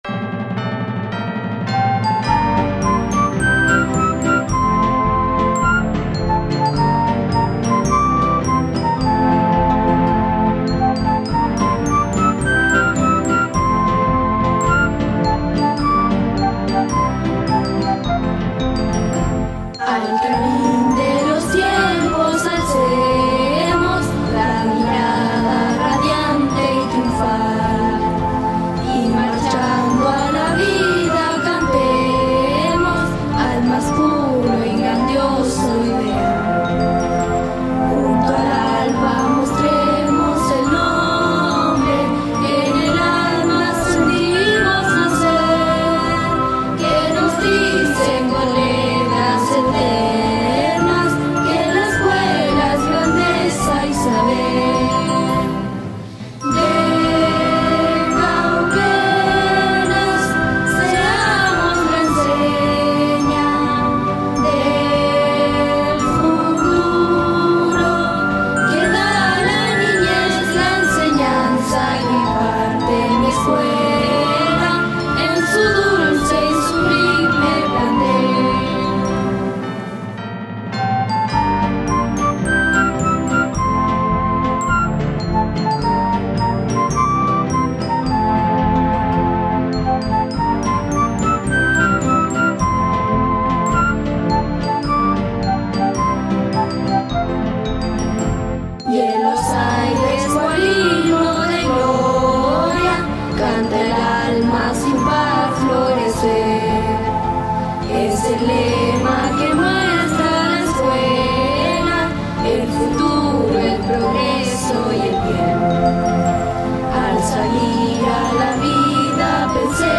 Himno Institucional